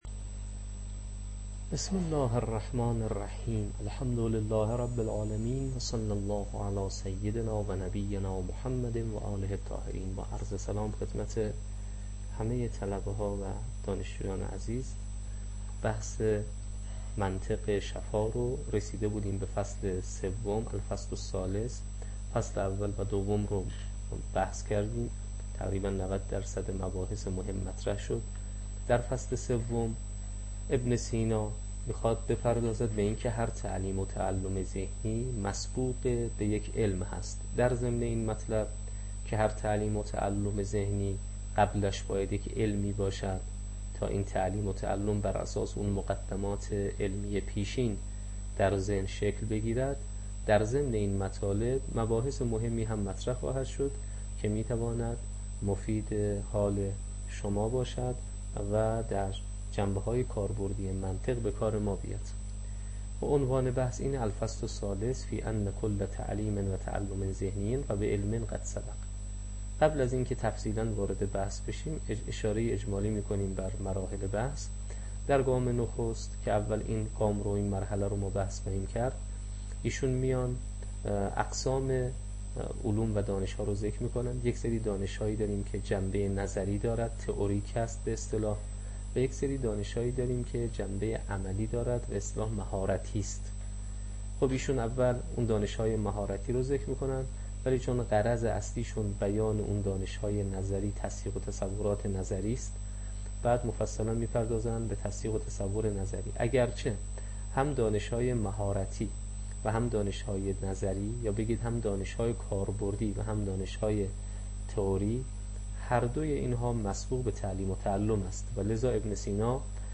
منطق شفاء، تدریس